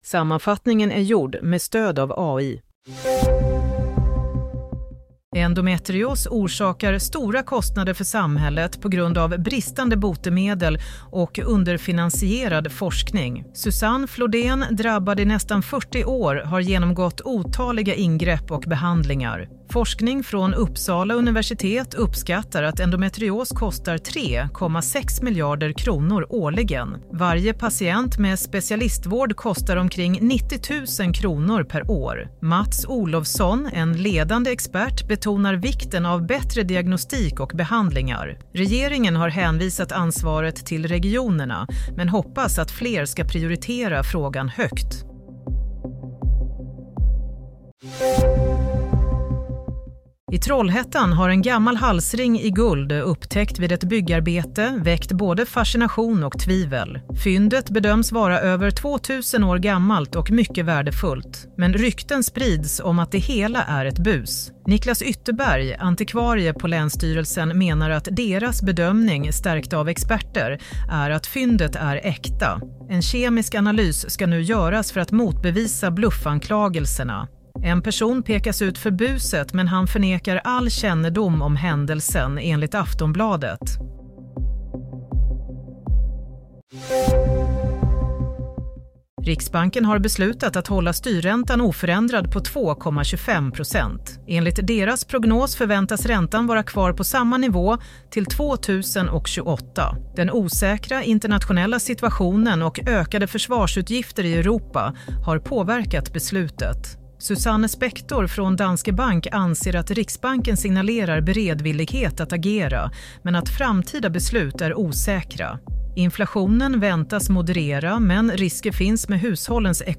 Play - Nyhetssammanfattning 20 mars 16.00